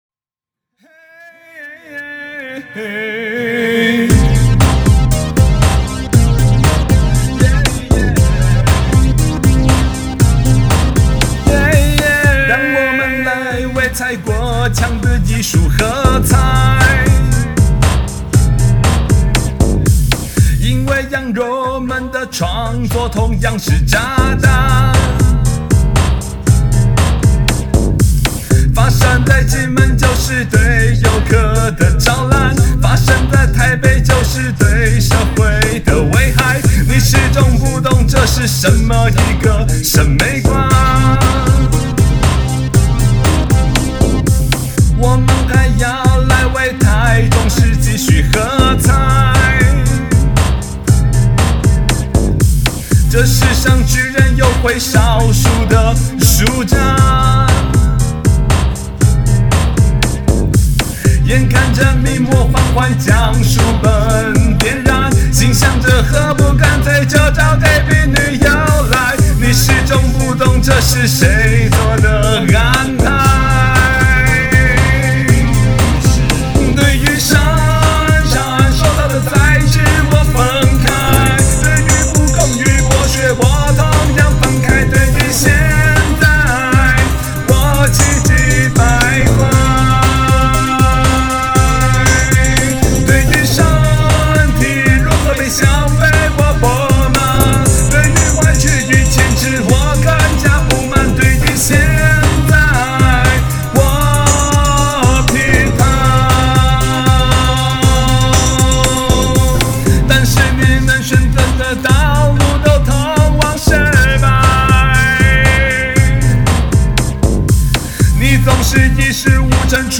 這配樂還真有電子花車的味道….
The song actually sounds catchy and has a pop feel.